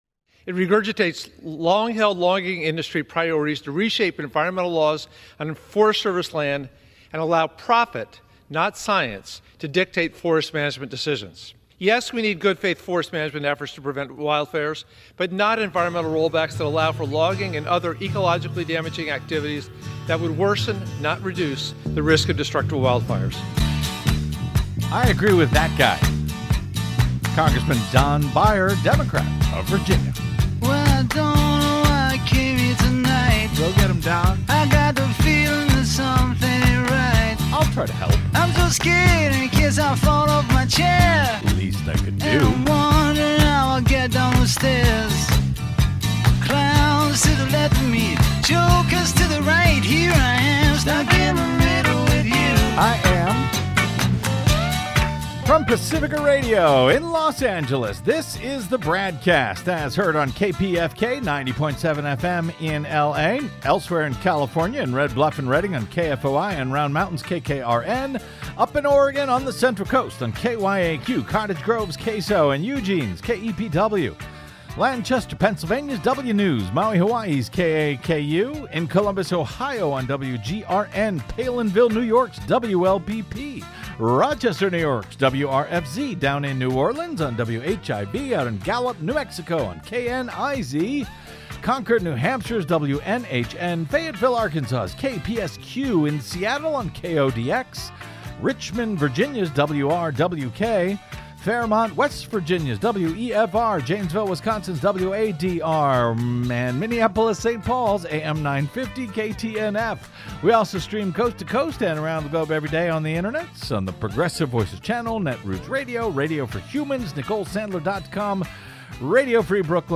Originating on Pacifica Radio's KPFK 90.7FM in Los Angeles and syndicated coast-to-coast and around the globe!